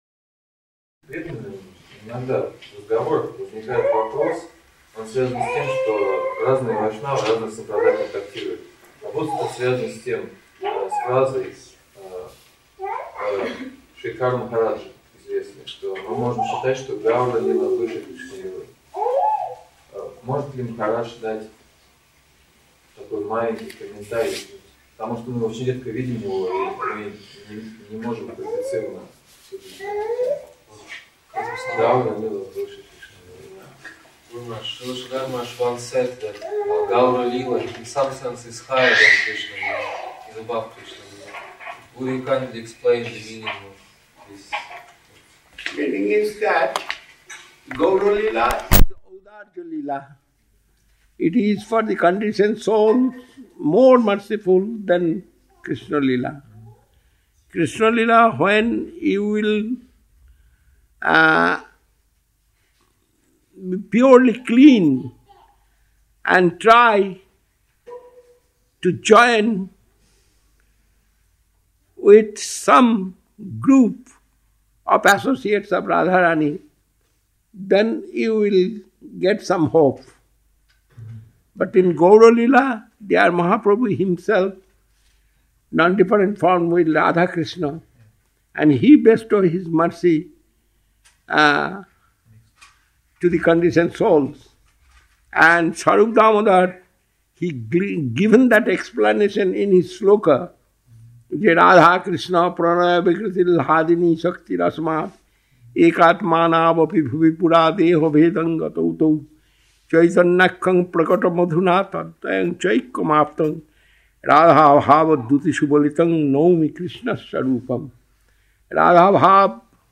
Какая лили Господа выше: Гаура-лила или Кришна-лила? Какие требования вы предъявляете к своим ученикам? Киртан Хари-харая.